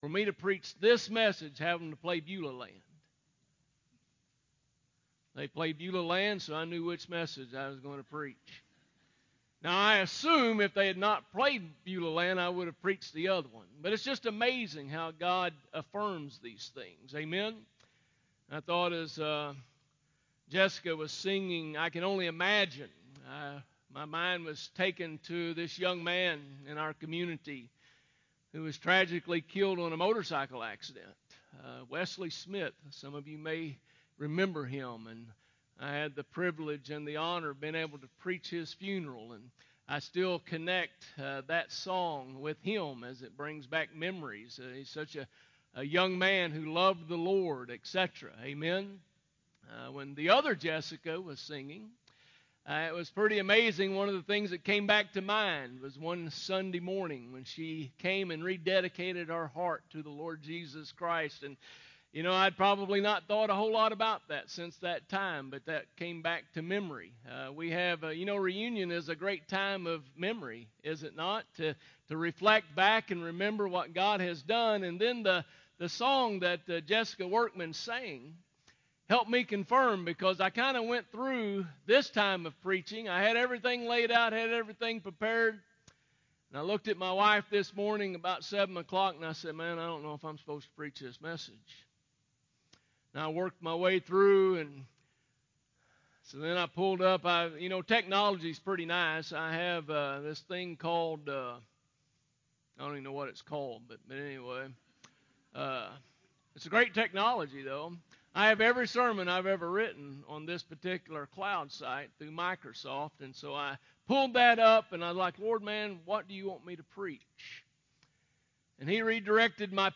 2021 1 Thessalonians 4: 13-18 ← Newer Sermon Older Sermon →